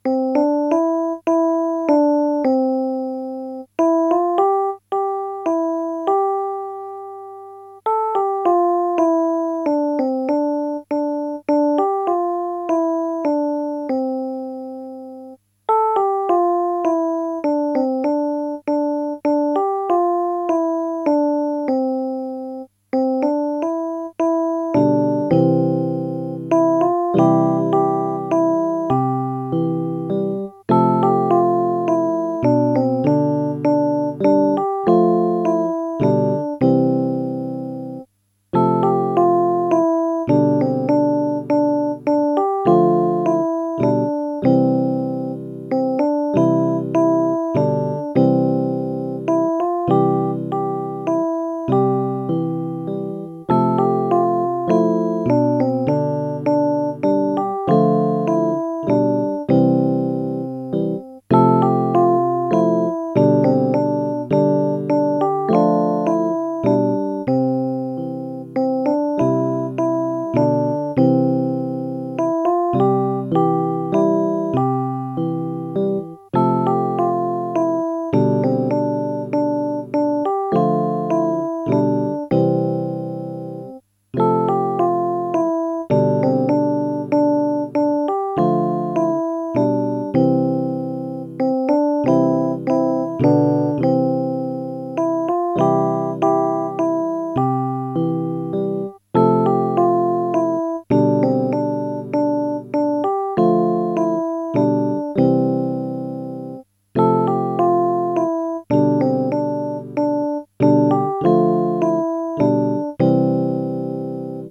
-         prvá sloha klavír pravá ruka
-         druhá sloha jednoduchý doprovod (klavír ľavá ruka)
-         bez spevu